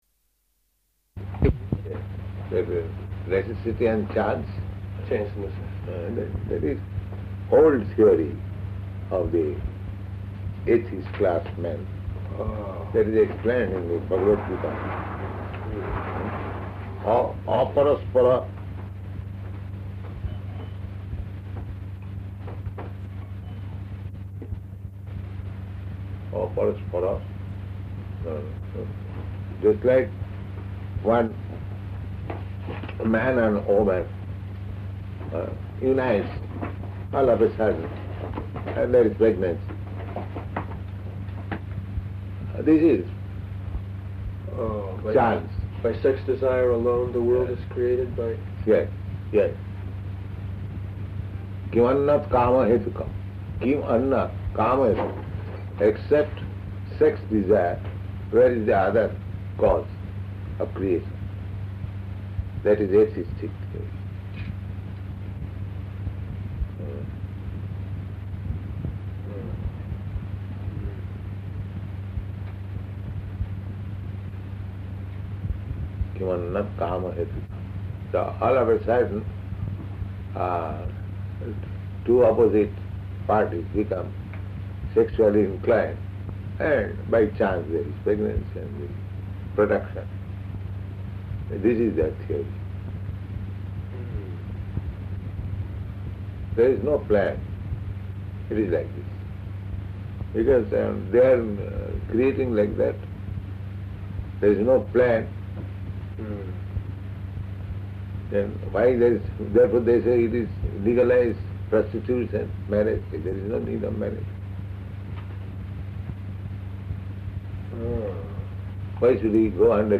Room Conversation
Location: Tokyo